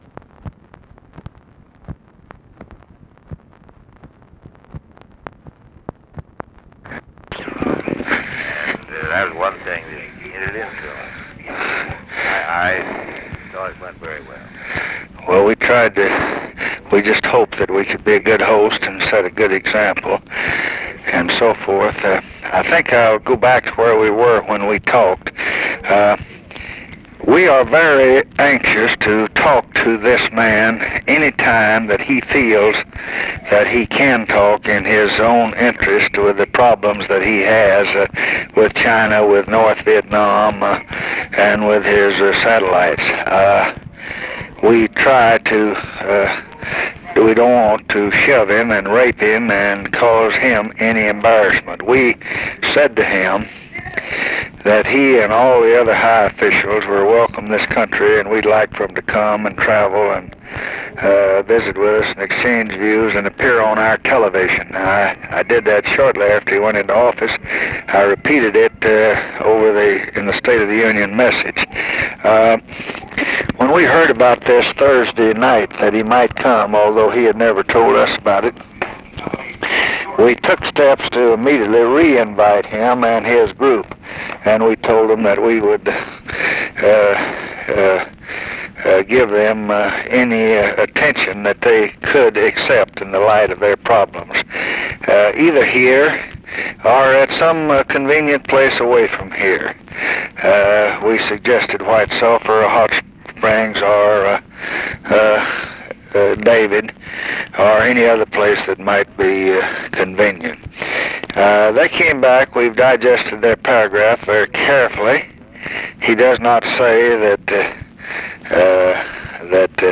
Conversation with WILLIAM FULBRIGHT, June 20, 1967
Secret White House Tapes